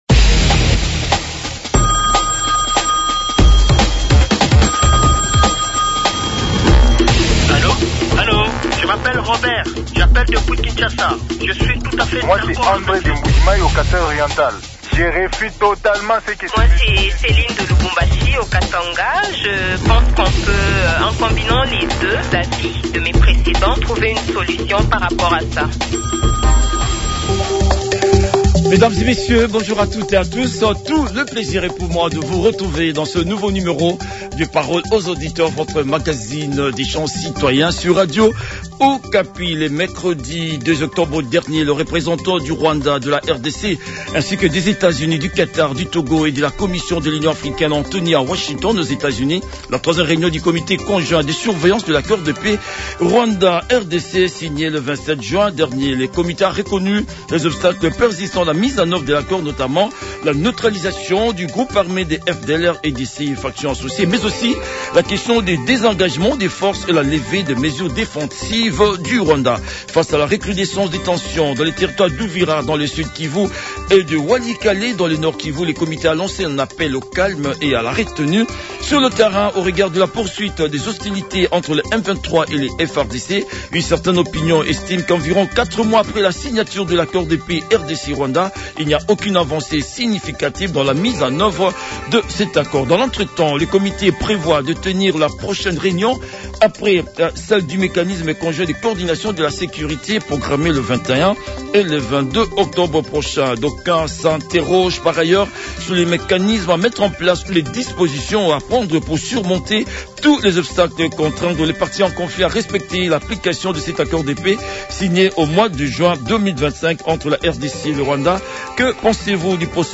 Les auditeurs ont débattu avec le professeur